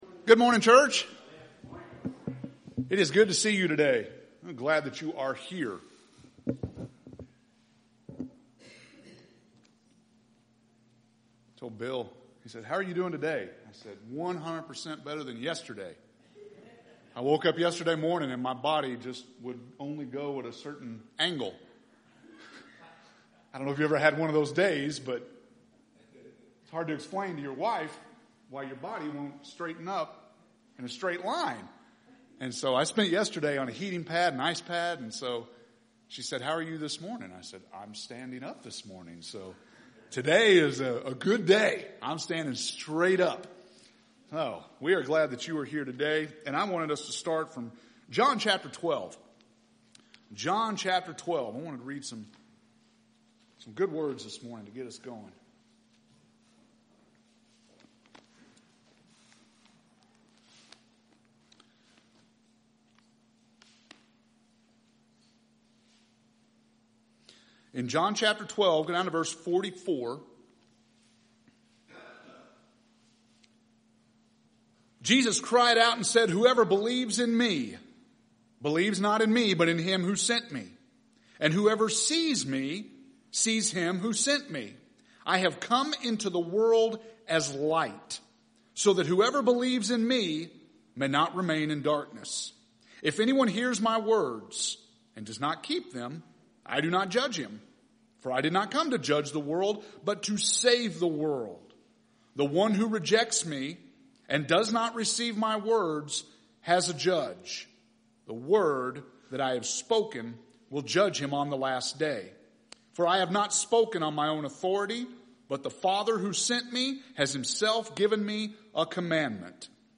August 2nd – Sermons